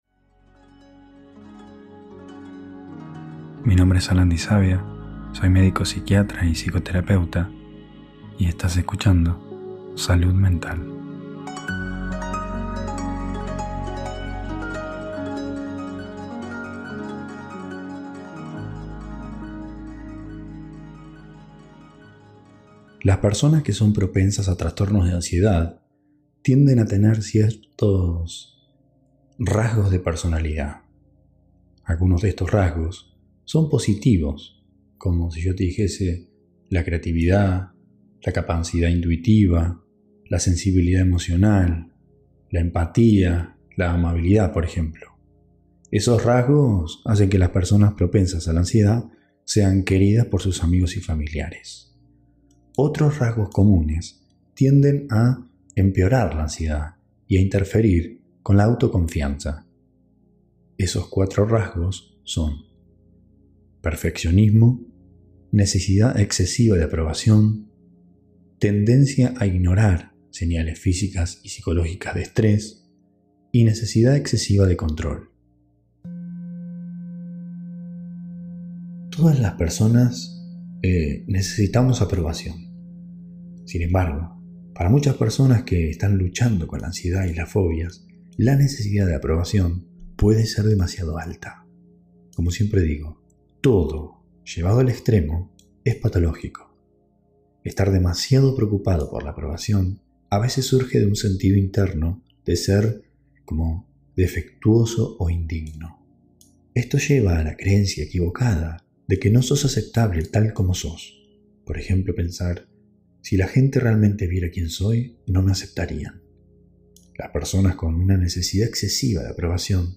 Extracto del taller.